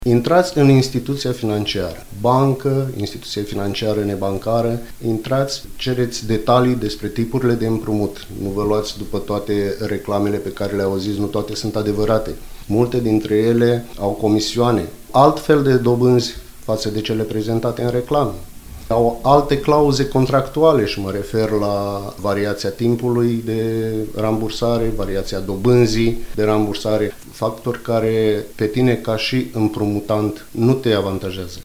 Invitat în studioul nostru de radio, el a spus că discuțiile cu un consilier financiar pot lămuri multe aspecte care altfel ar cauza pierderi considerabile.